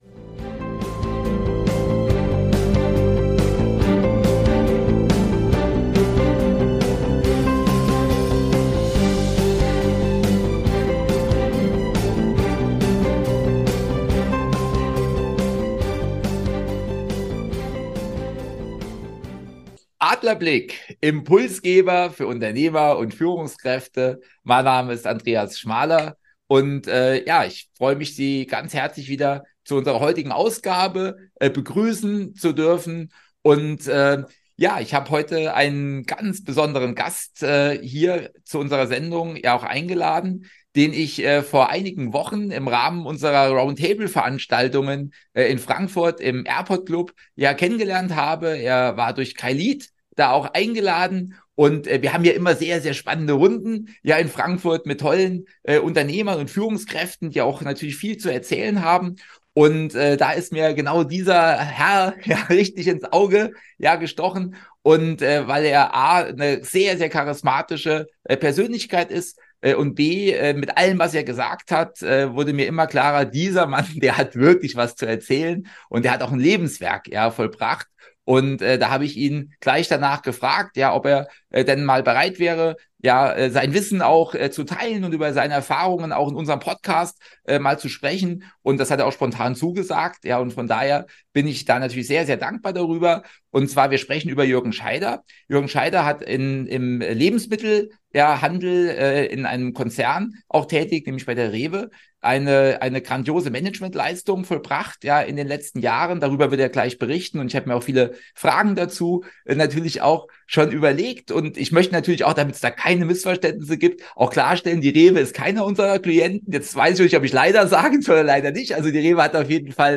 Beschreibung vor 2 Jahren Was heißt es, einen Handelskonzern aus der Lebensmittelbranche zu führen und weiterzuentwickeln? Was bedeuten hier Strategie, Kultur und Führung? In einem spannenden Gespräch